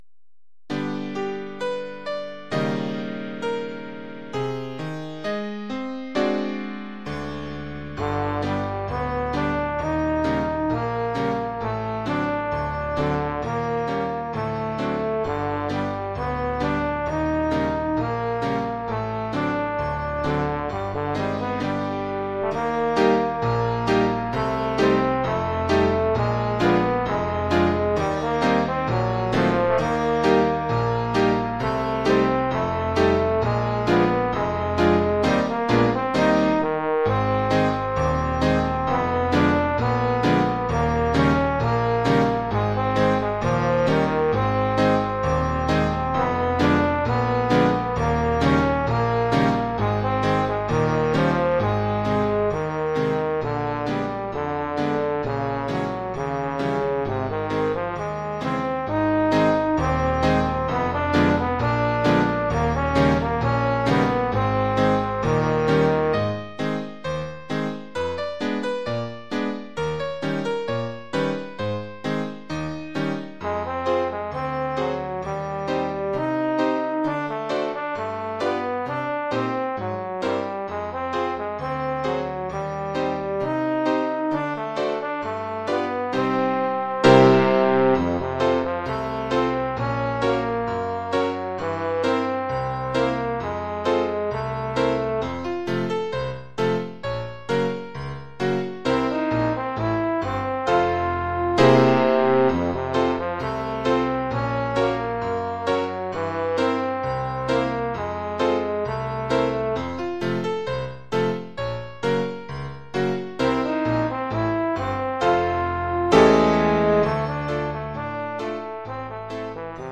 Oeuvre pour trombone et piano.